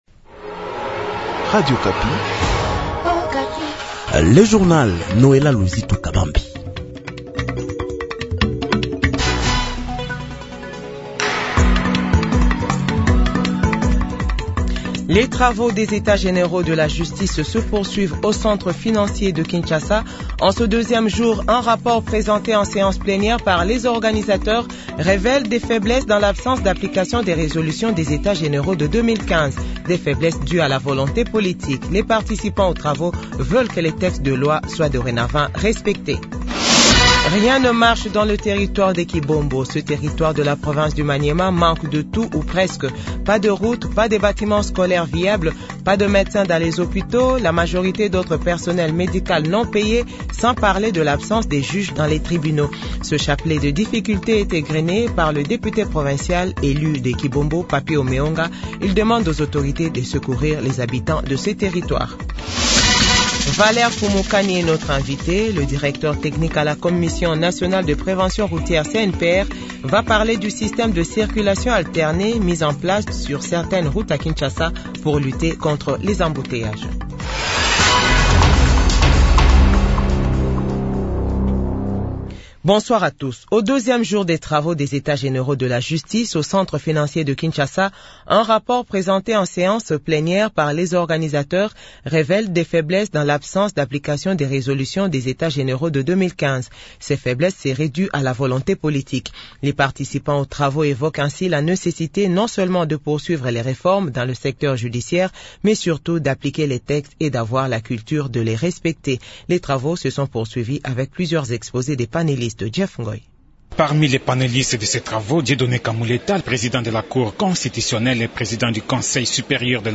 JOURNAL FRANÇAIS DE 18H00 | Radio Okapi